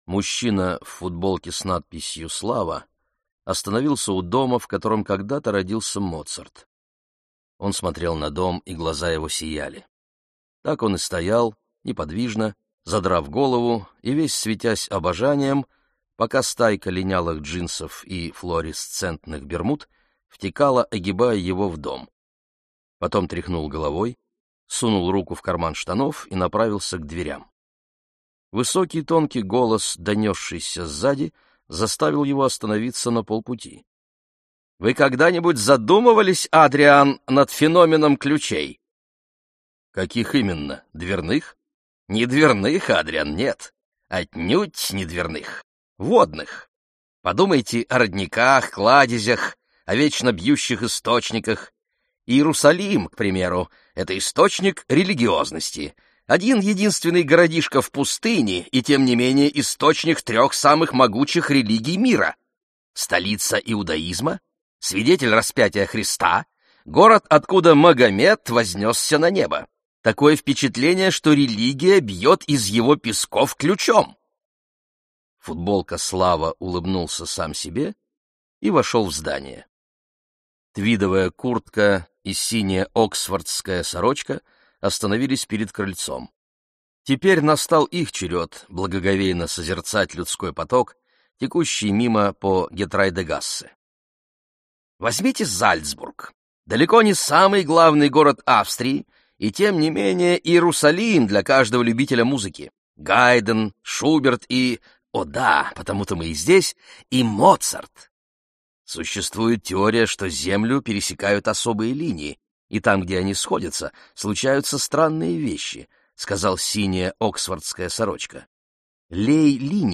Аудиокнига Лжец | Библиотека аудиокниг